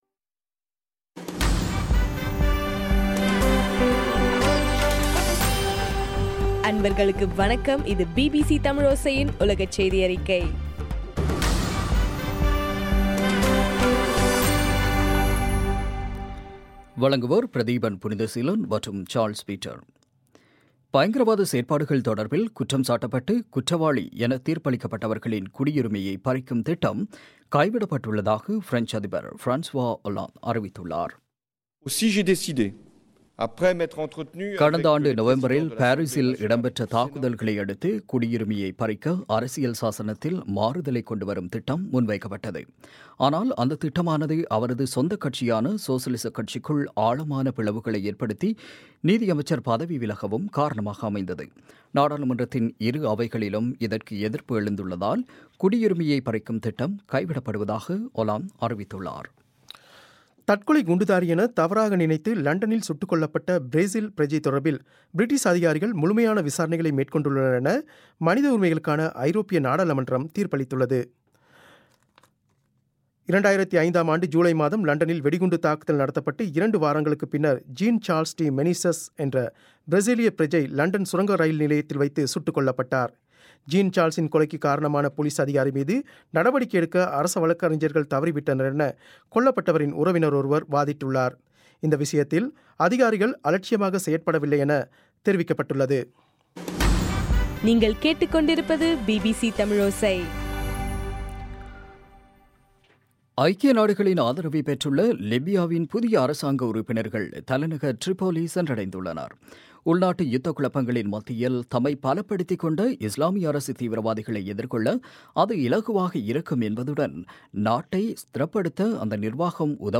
மார்ச் 30, 2016 பிபிசி செய்தியறிக்கை